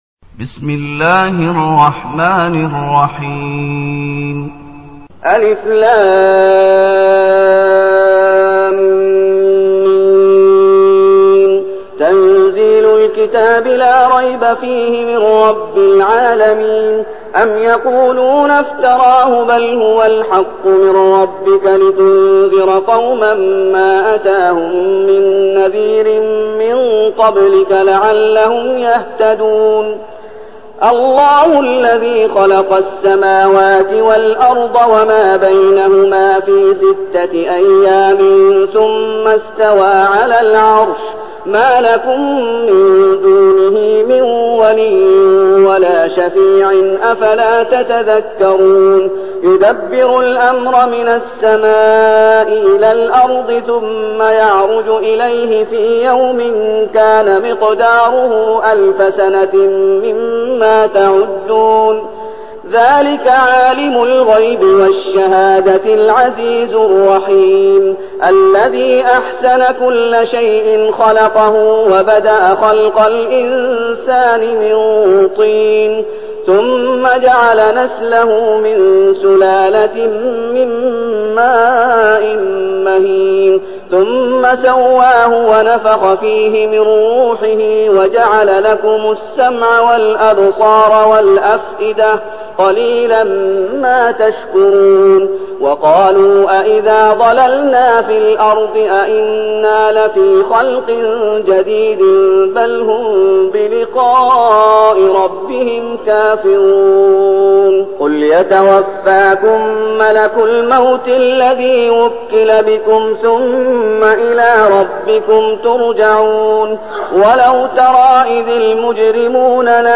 المكان: المسجد النبوي الشيخ: فضيلة الشيخ محمد أيوب فضيلة الشيخ محمد أيوب السجدة